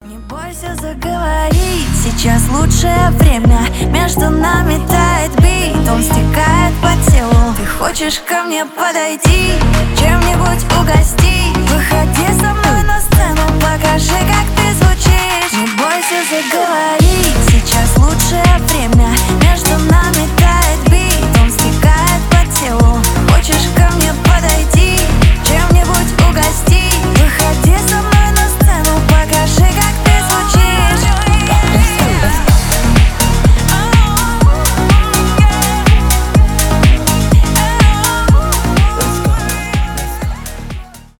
поп , клубные